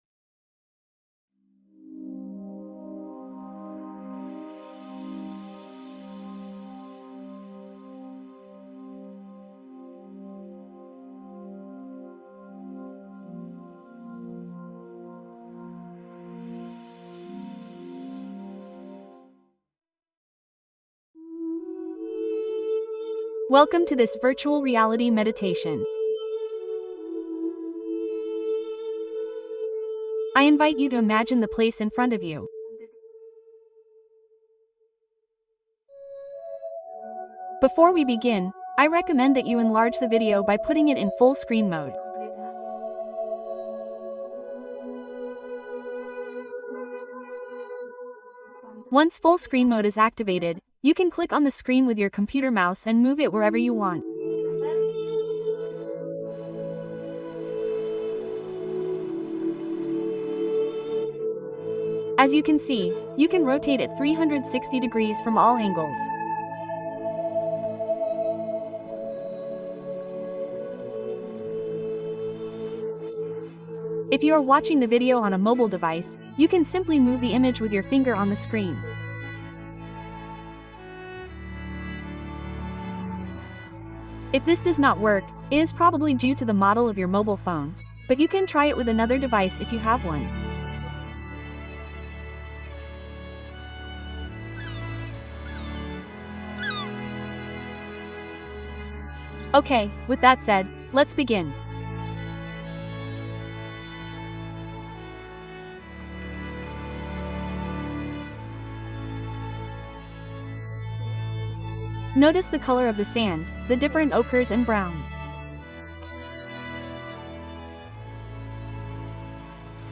Relajación 360º | Meditación inmersiva para soltar tensión